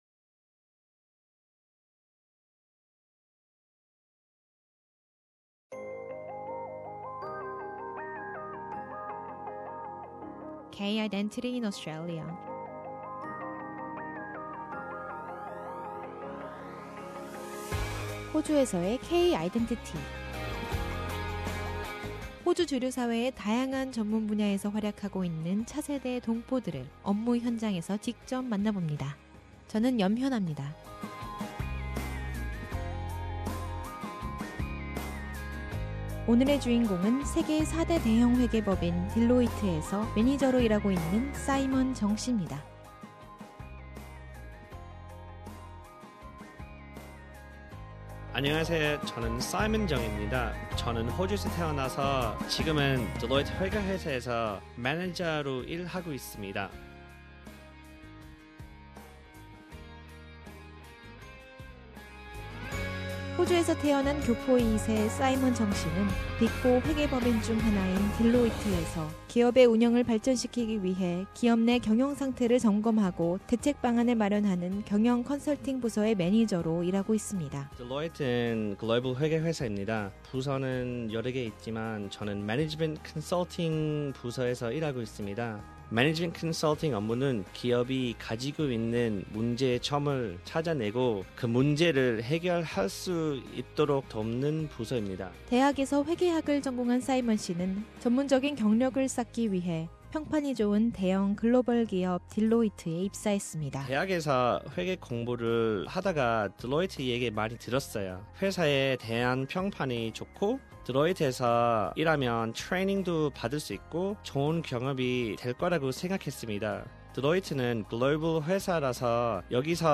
SBS Korean